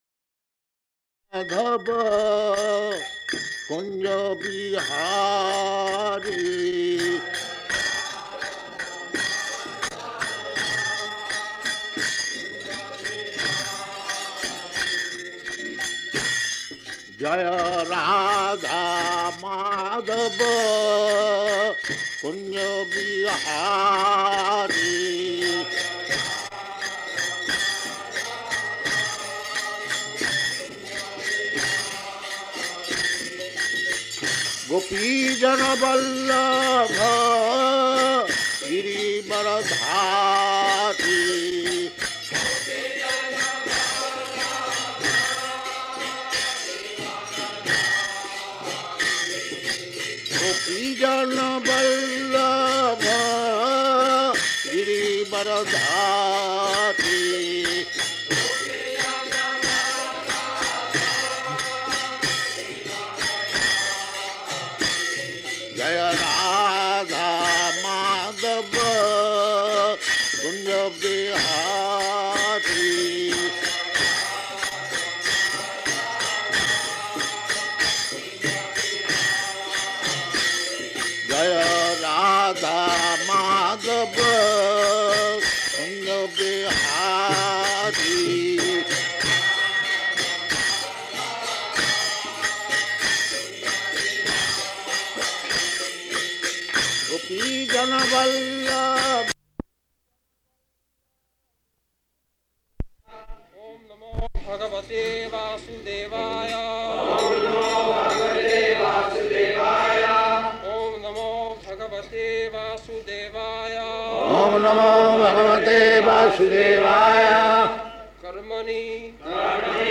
April 7th 1974 Location: Bombay Audio file
[Prabhupāda and devotees repeat] [leads chanting of verse]